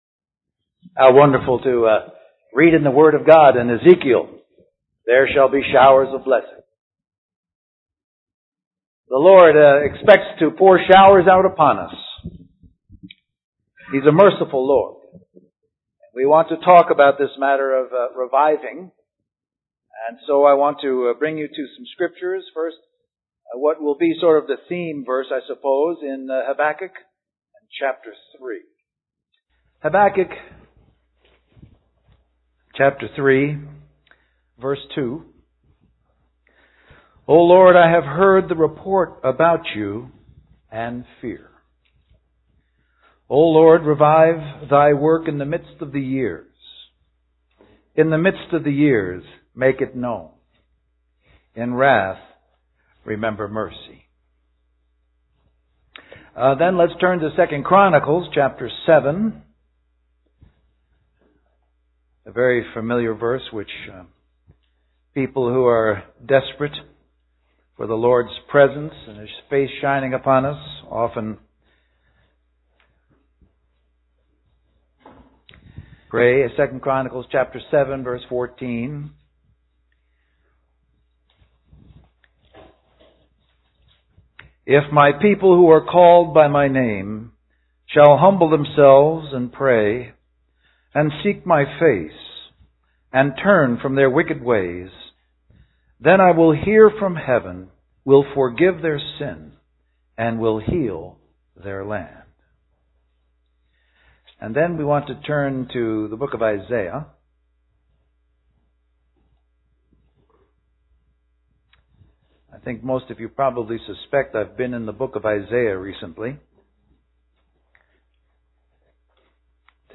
A collection of Christ focused messages published by the Christian Testimony Ministry in Richmond, VA.
2008 Western Christian Conference